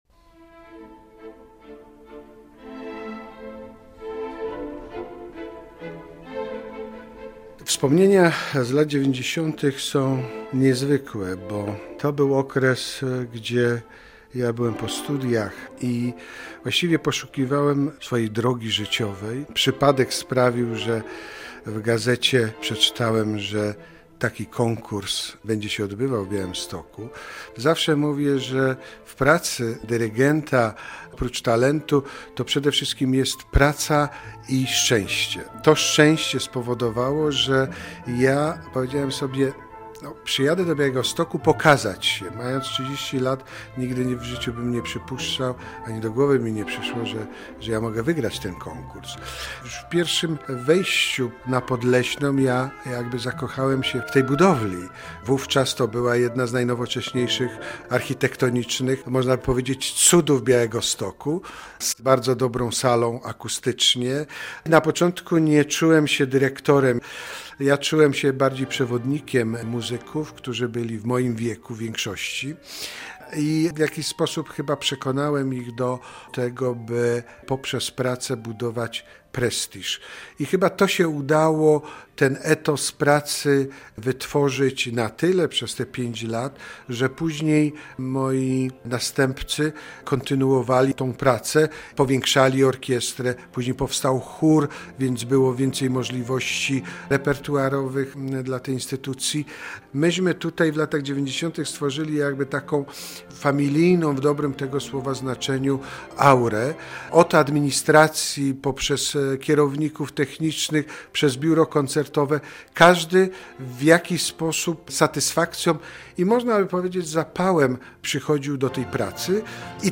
Orkiestra Opery i Filharmonii Podlaskiej obchodzi 65-lecie - relacja